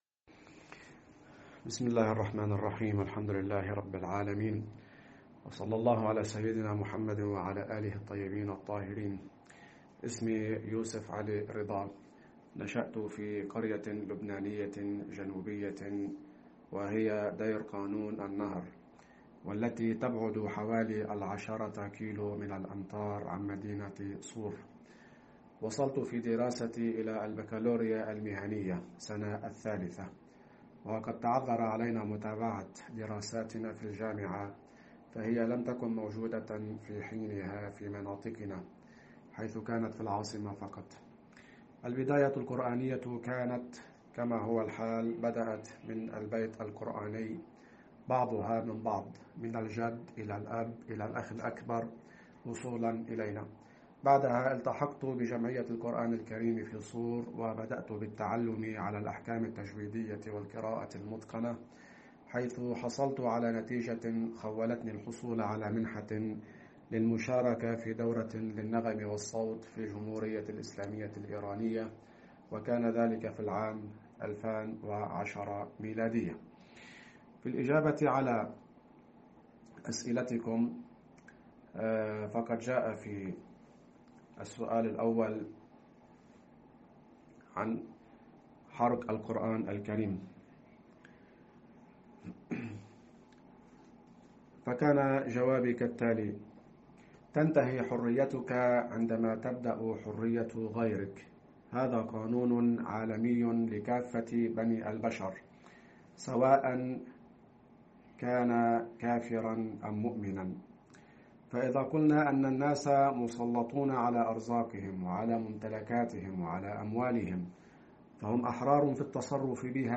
حواراً